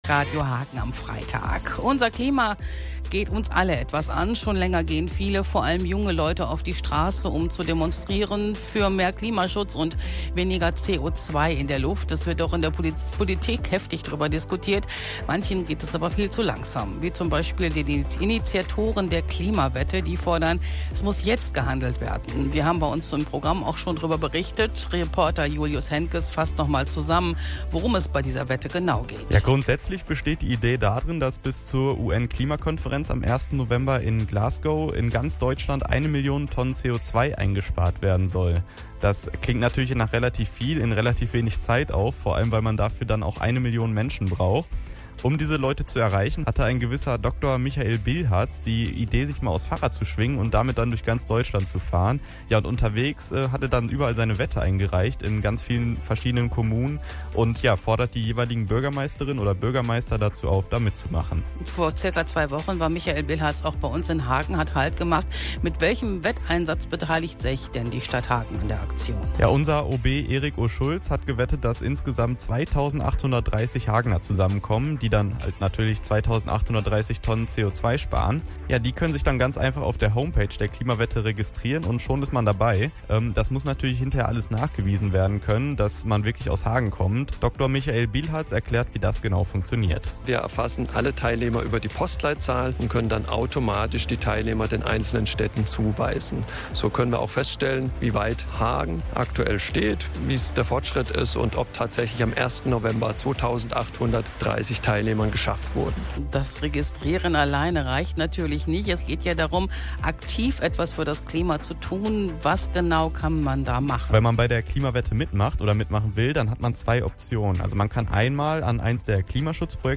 Talk: Die Klimawette in Hagen - Radio Hagen